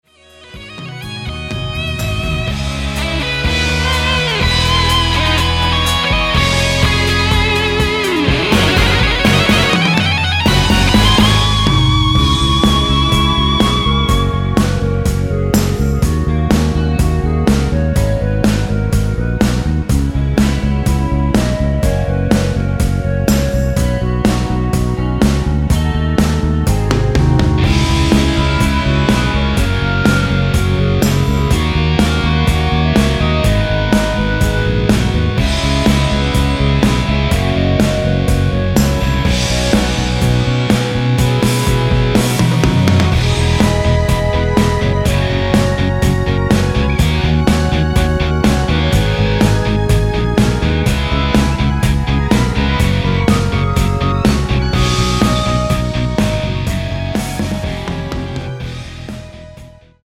원키 멜로디 포함된 MR입니다.(미리듣기 참조)
G#m
앞부분30초, 뒷부분30초씩 편집해서 올려 드리고 있습니다.
위처럼 미리듣기를 만들어서 그렇습니다.